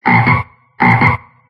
Frog.ogg